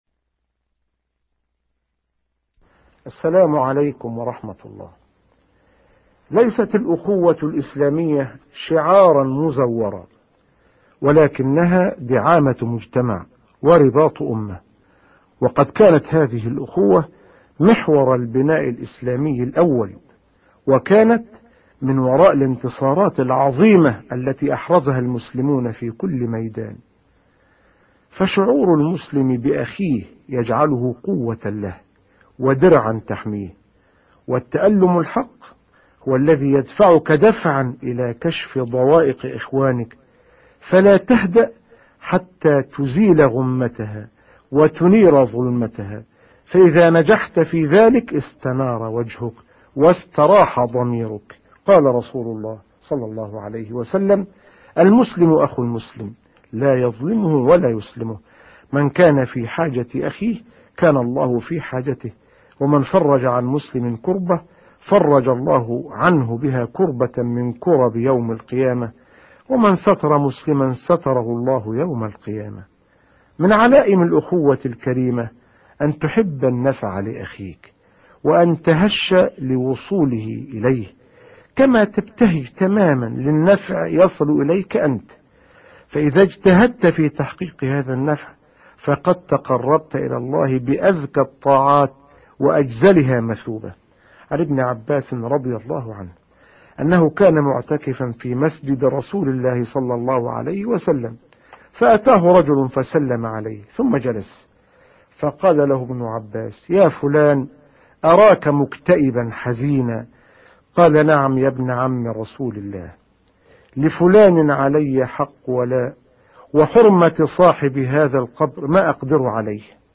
في هذه الصفحة تجدون مجموعة من البرامج الإذاعية التي تنتجها وتقوم بتسجيلها منظمة إذاعات الدول الإسلامية
ادع الى سبيل ربك السعودية اعداد وتقديم الشيخ محمد الغزالي